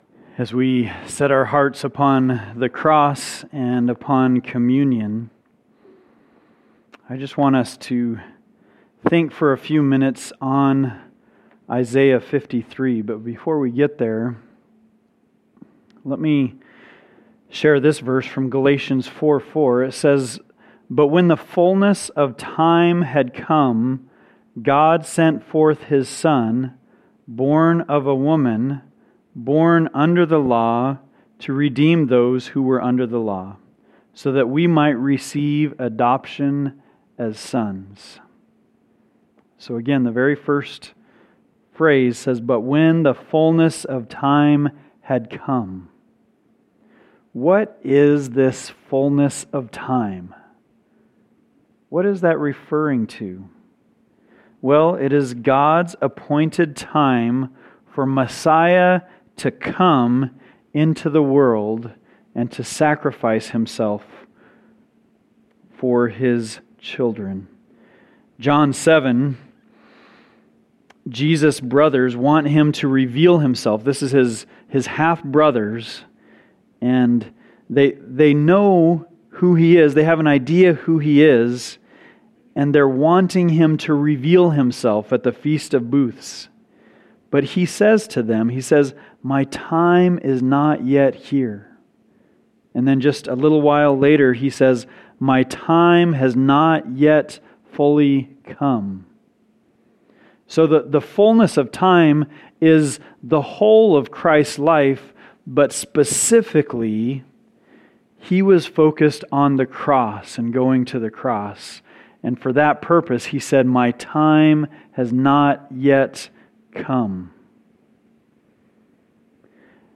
Maunday-Thursday-Sermon.mp3